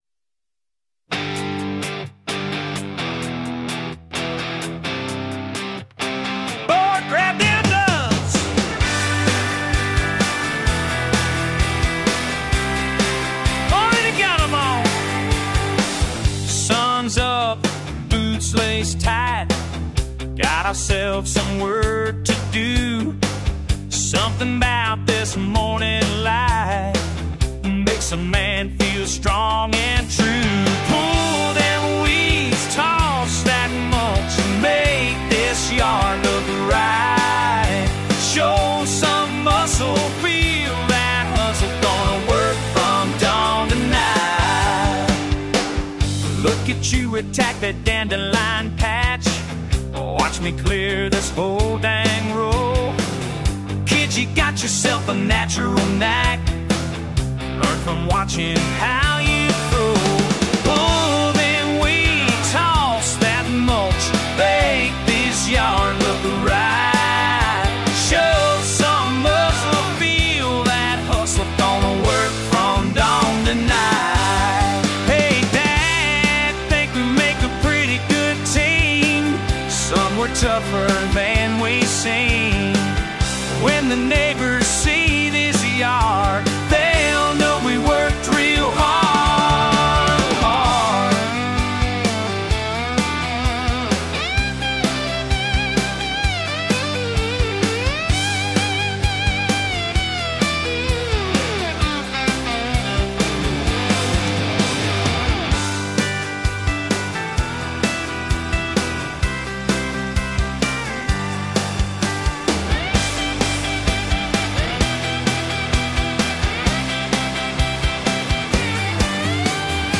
AI Music Co-Creating
Producer lets us “chat” with the music maker, make changes, explore limits of the model (if I ask for an older man it still sounds like the singer is in his 20’s - haha).